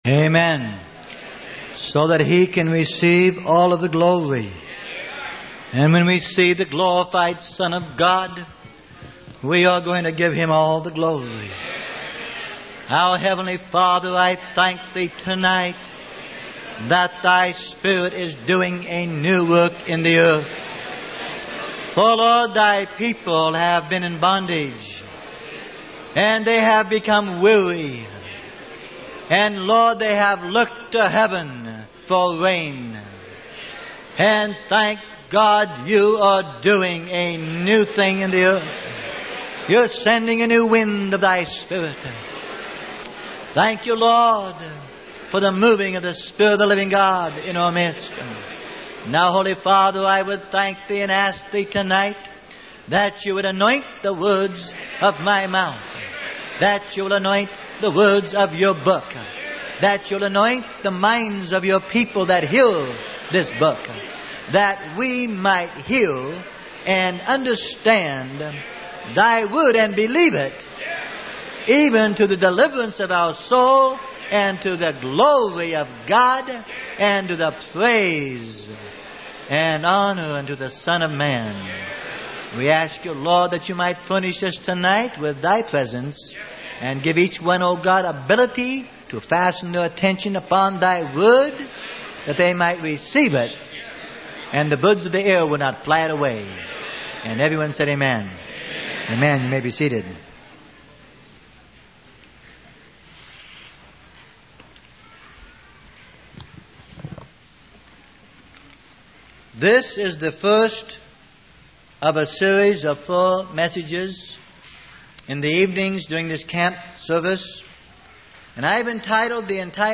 Sermon: HAVING FELLOWSHIP WITH THE SON OF MAN - PART 1: PROVING THE PREMISE - Freely Given Online Library